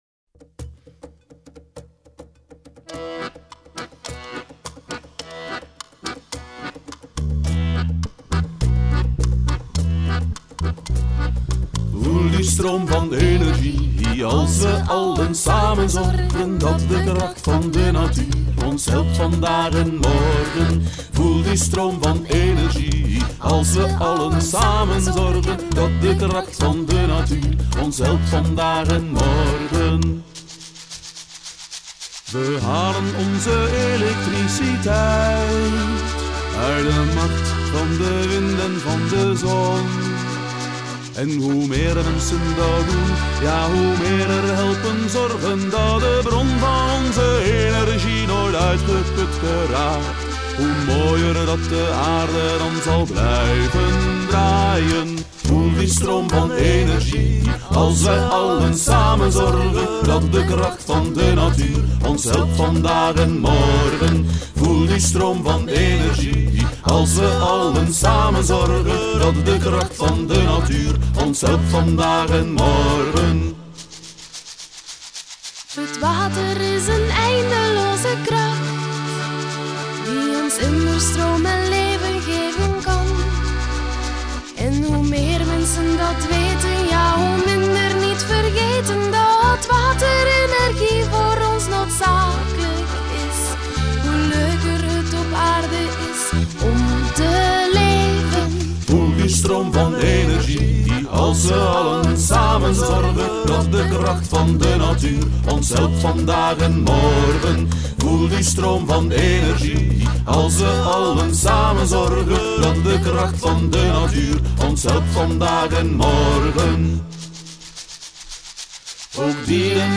Zingen jullie mee met dit vrolijke lied over energie?